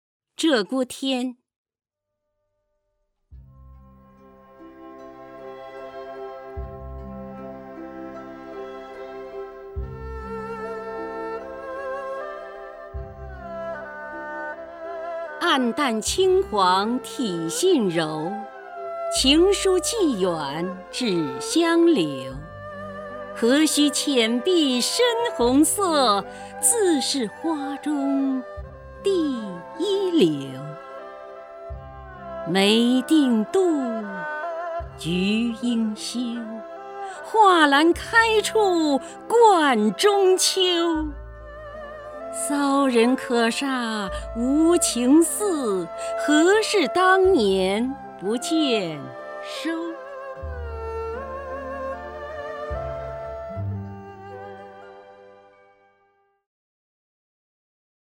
首页 视听 名家朗诵欣赏 姚锡娟
姚锡娟朗诵：《鹧鸪天·暗淡轻黄体性柔》(（南宋）李清照)　/ （南宋）李清照